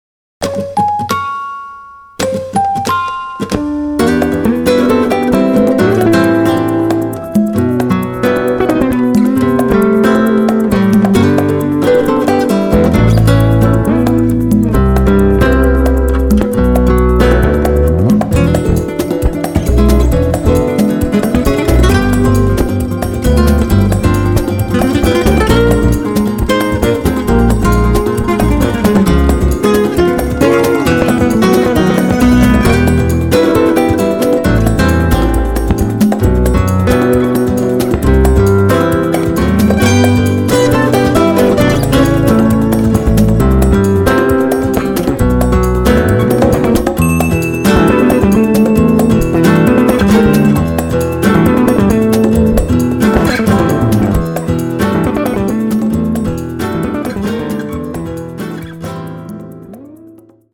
flamenco-gitarre, laúd, perc.
kontrabass, e-bass
percussion